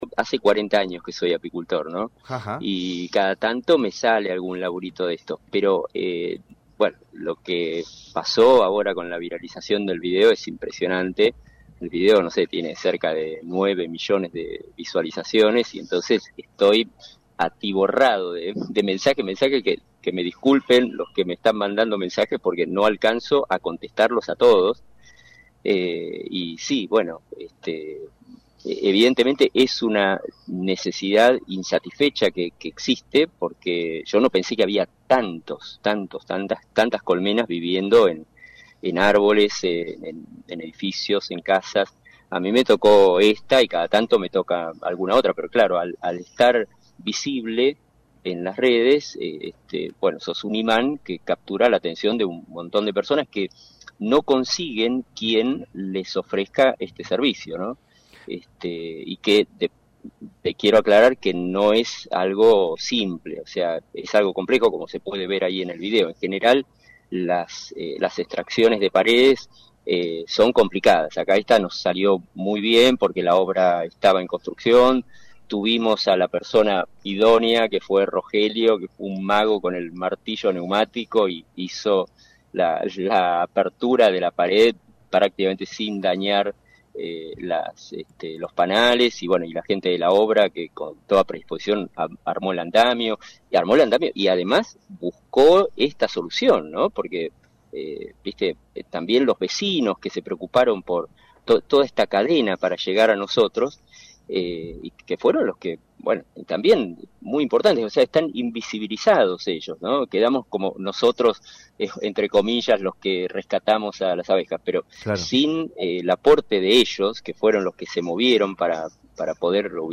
en el programa Media Mañana